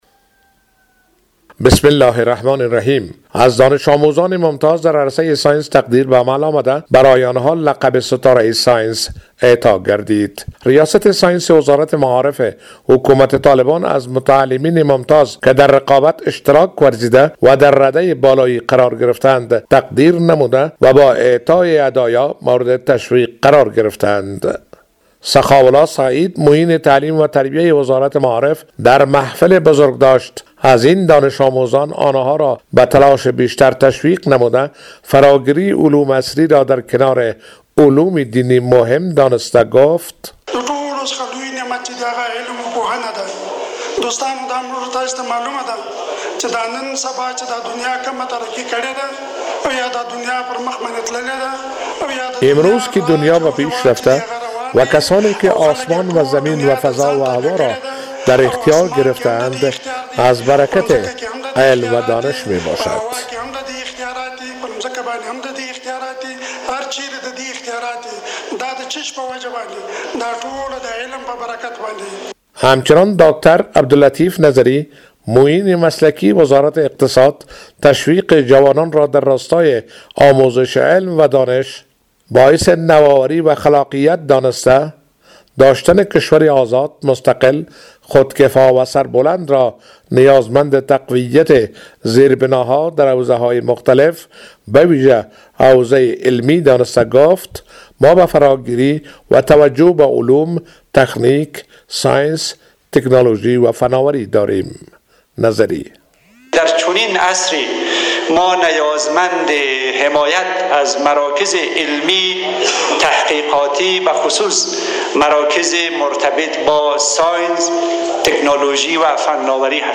خبر